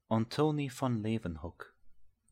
Antoni van Leeuwenhoek [ˈantoːnɛɪ̯ ˈvɑn ˈleːwənhuk] (
Aussprache/?)
LeeuwenhoekPronunciation.ogg.mp3